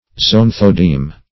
Search Result for " zoanthodeme" : The Collaborative International Dictionary of English v.0.48: Zoanthodeme \Zo*an"tho*deme\, n. [See Zoantharia , and Deme .]